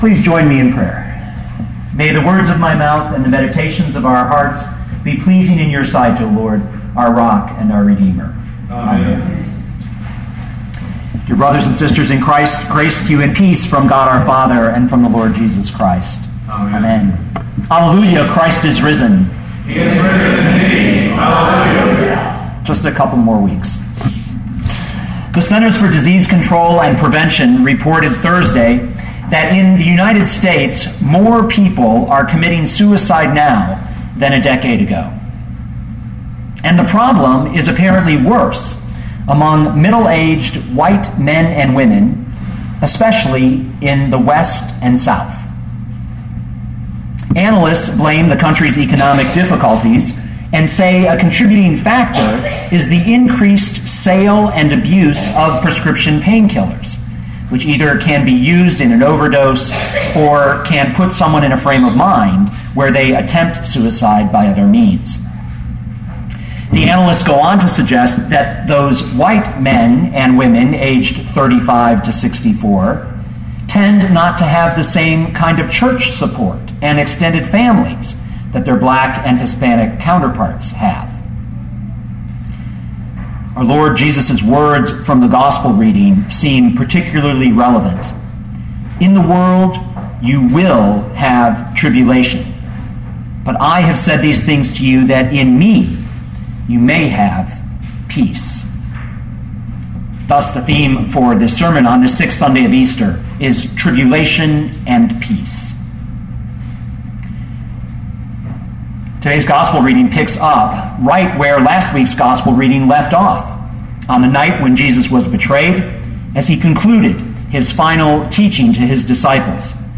2013 John 16:23-33 Listen to the sermon with the player below, or, download the audio.